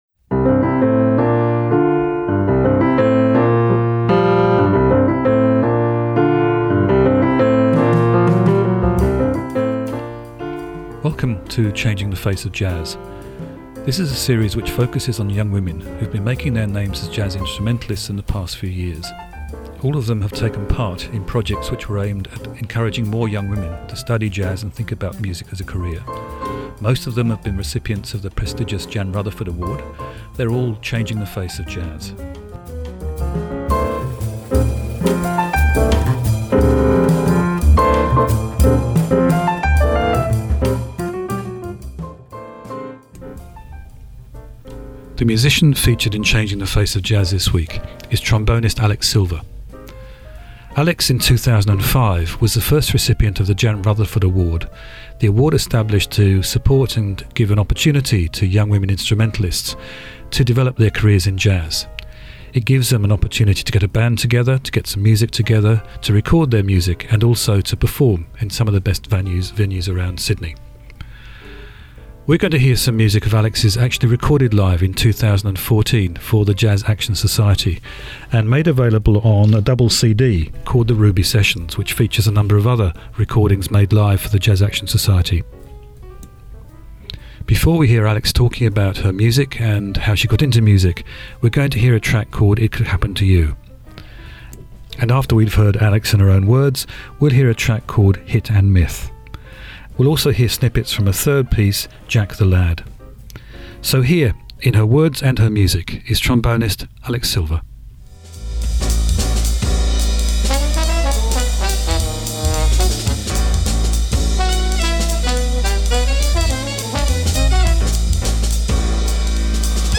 Trombone player, and band leader